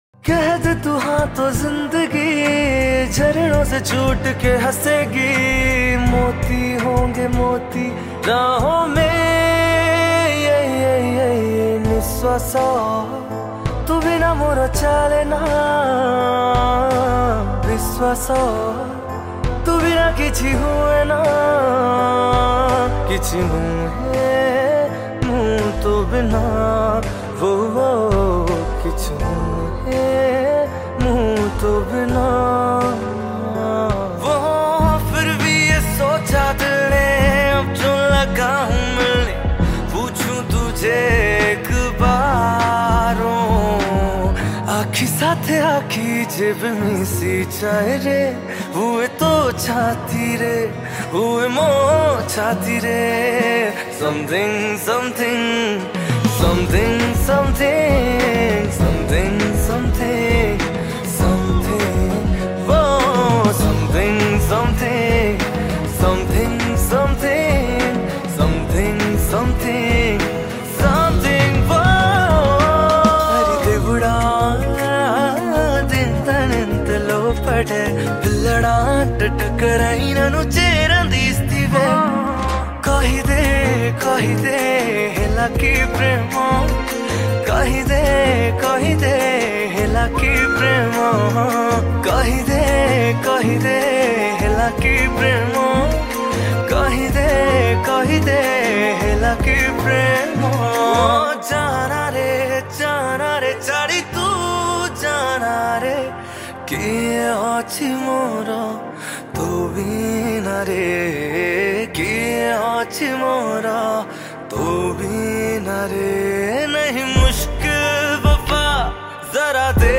Category : Cover Mp3 Song